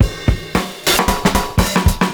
112FILLS01.wav